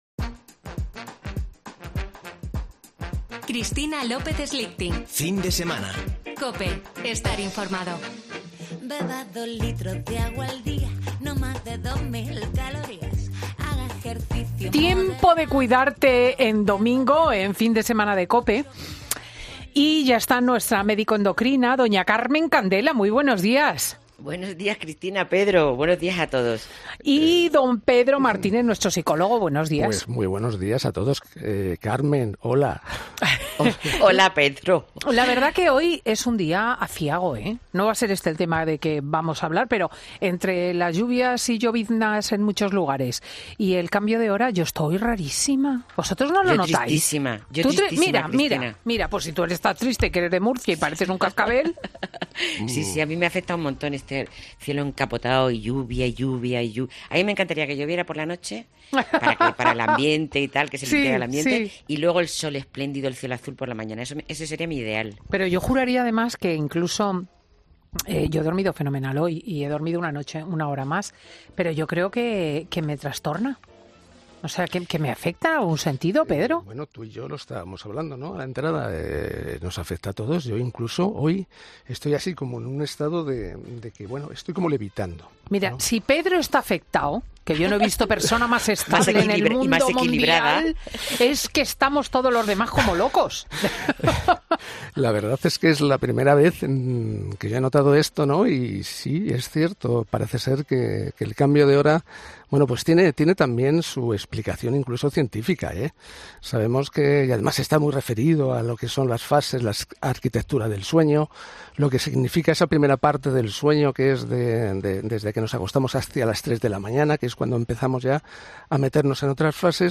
han ahondado en ello en los micrófonos de Fin de Semana con Cristina